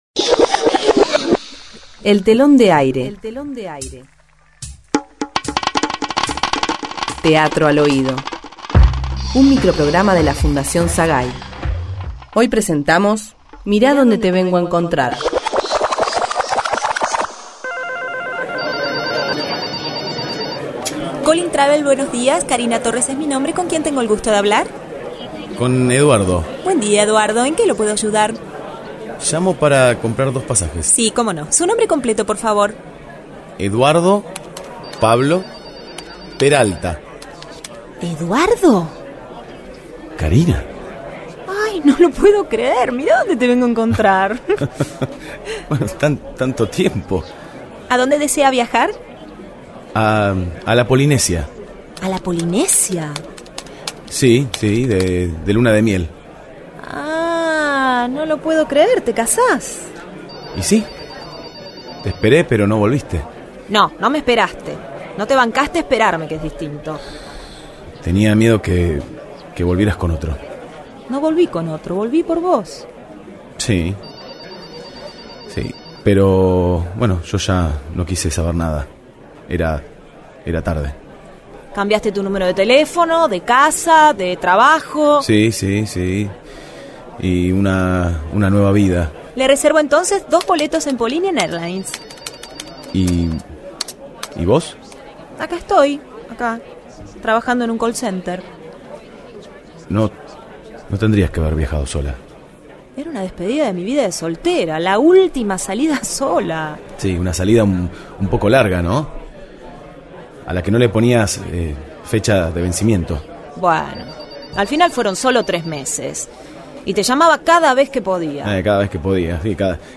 Título: Mira donde te vengo a encontrar. Género: Ficción. Sinopsis: El desencuentro los envuelve, la distancia los separa, la casualidad los enfrenta…
grabación en estudio